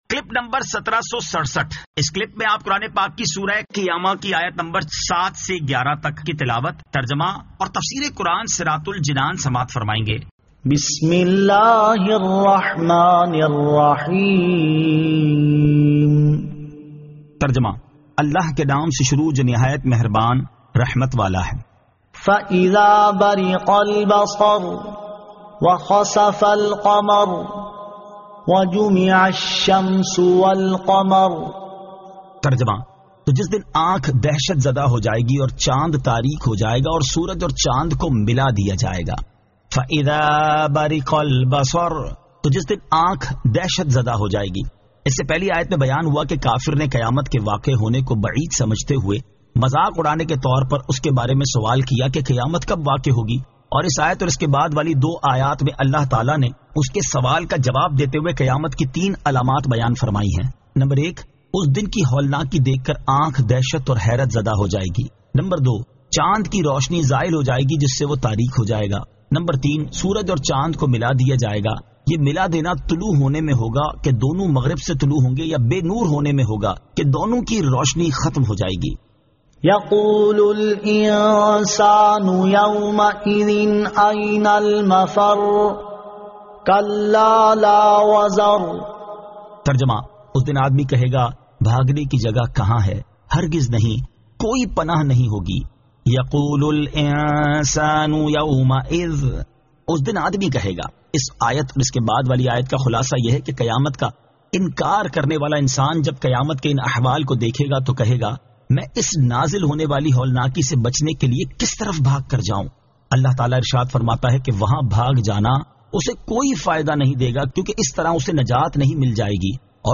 Surah Al-Qiyamah 07 To 11 Tilawat , Tarjama , Tafseer